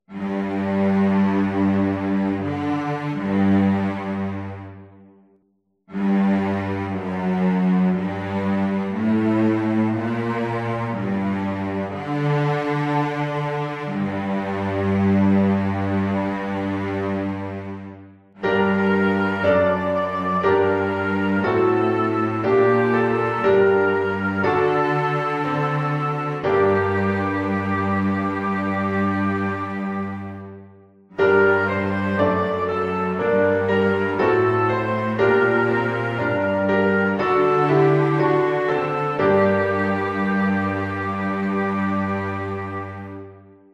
uitwerking van een bas